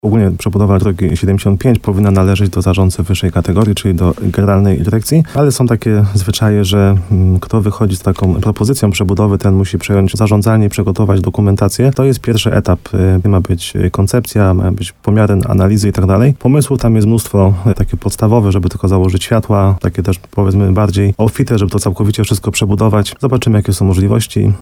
– Zarząd powiatu podpisał porozumienie w sprawie przejęcia obowiązków zarządcy na tym odcinku – mówił w programie Słowo za Słowo na antenie RDN Nowy Sącz